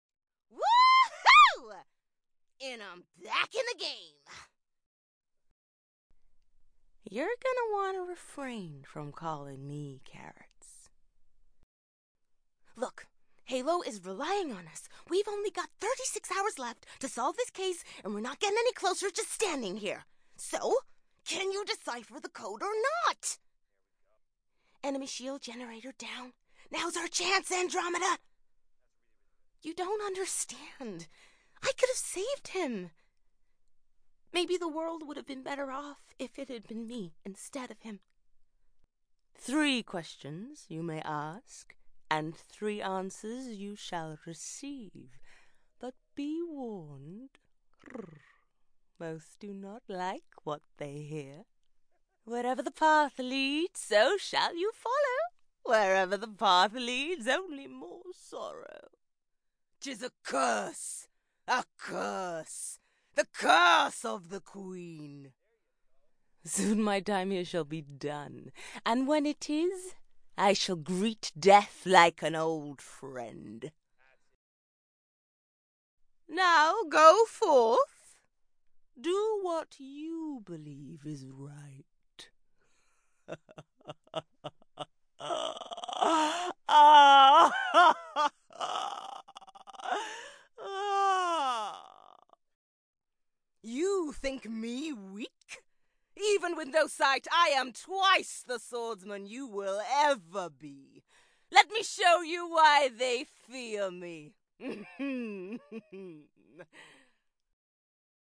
Video Game Character Reel